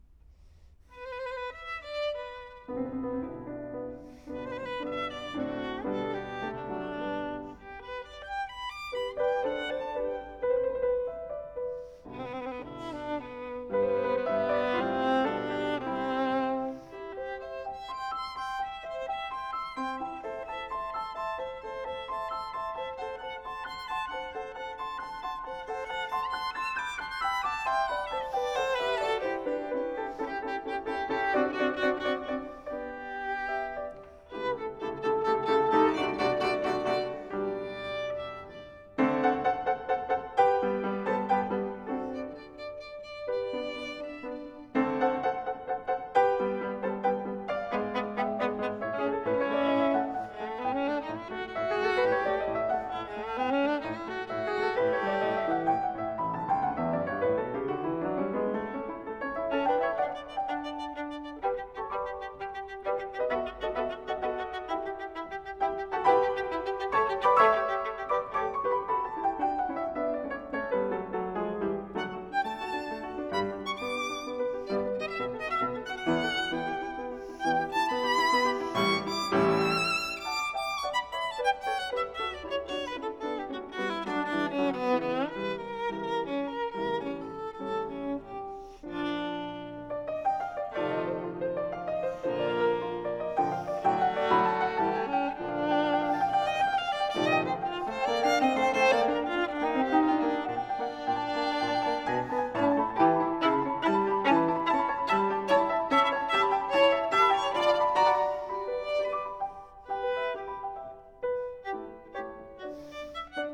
OK so here's the 3 separate mic pairs, raw, straight off the F8:
and finally the Calrecs (X/Y spots on the violin):